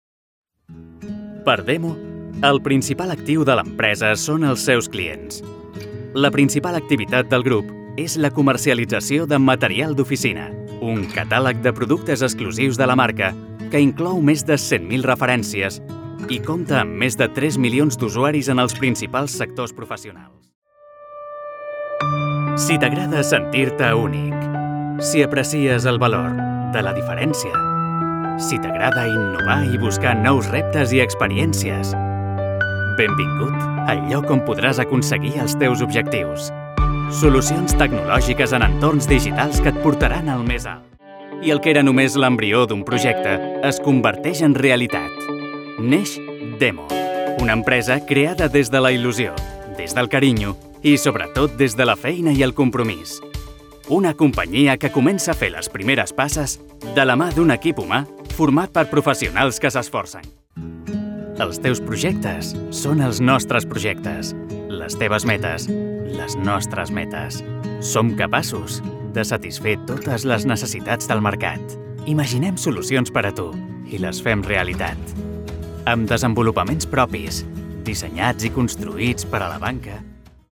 Jeune, Naturelle, Distinctive, Urbaine, Cool
Corporate